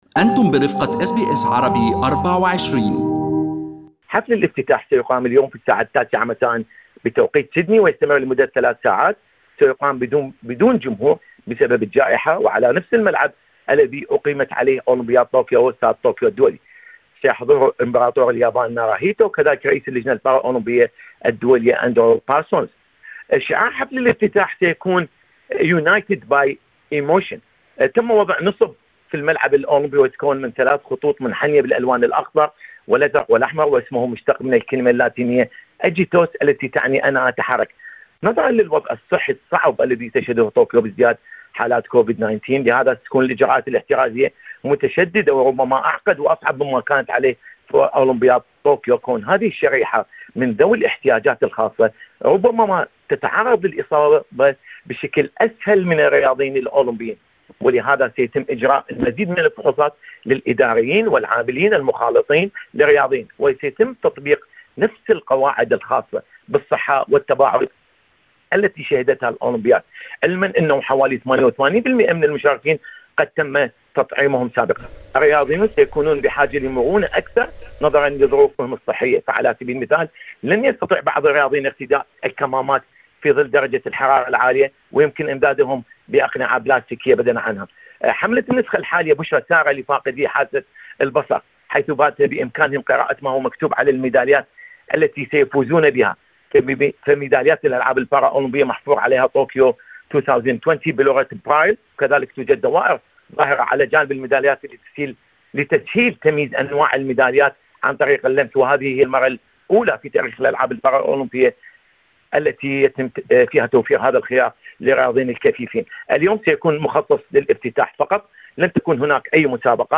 اللقاء